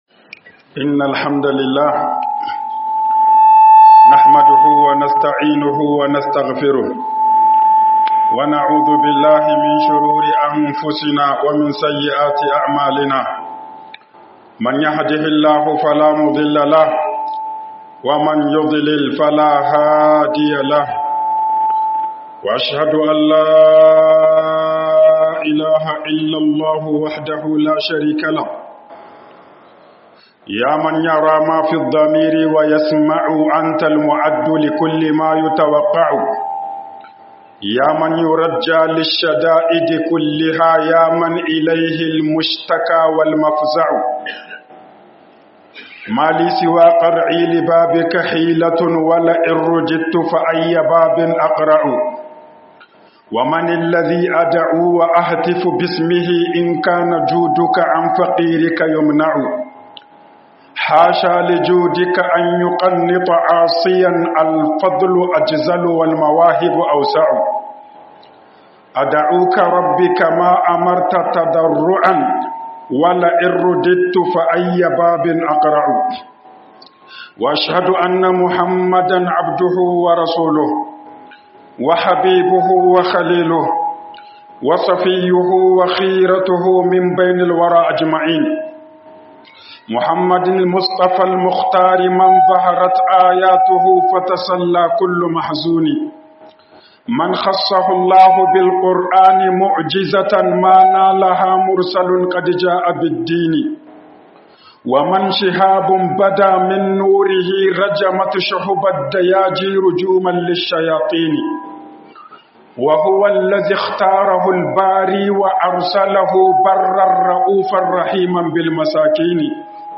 NASIHOHI TARA GA IYAYEN YARA - HUƊUBOBIN JUMA'A